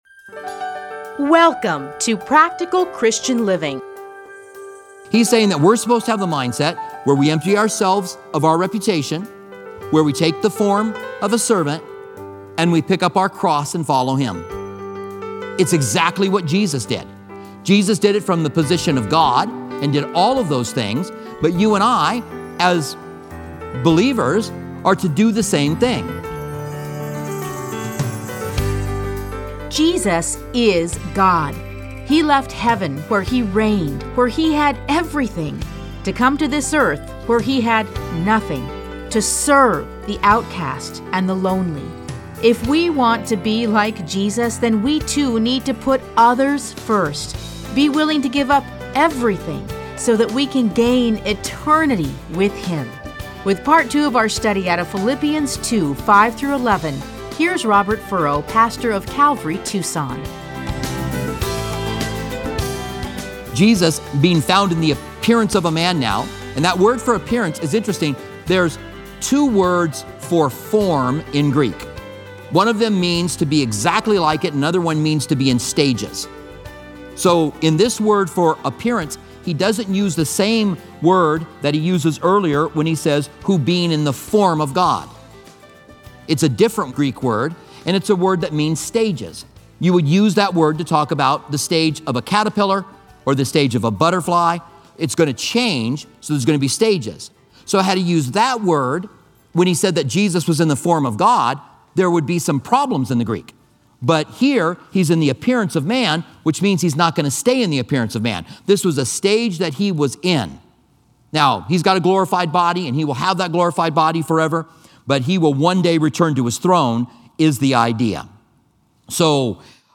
Listen to a teaching from A Study in Philippians 2:5-11.